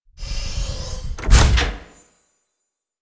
tbd-station-14/Resources/Audio/Machines/airlock_close.ogg
airlock_close.ogg